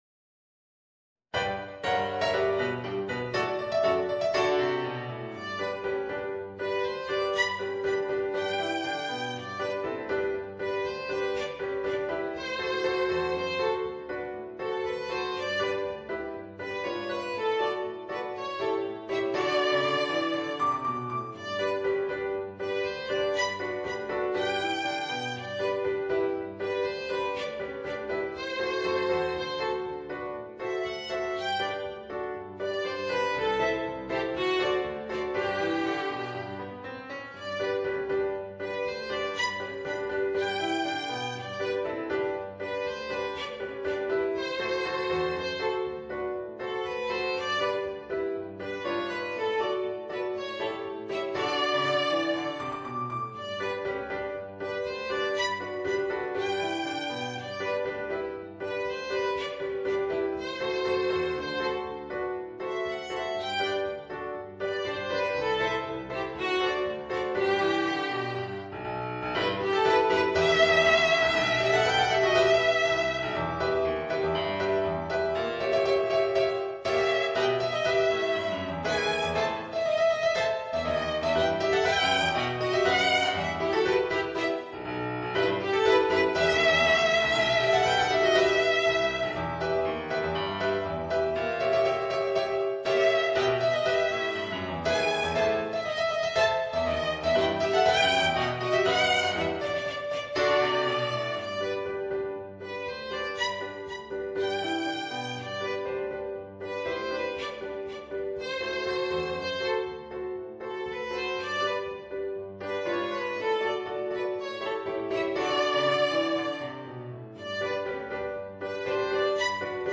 ヴァイオリン＋ピアノ